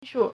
曙 shǔ
shu3.mp3